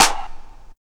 Snare (7).wav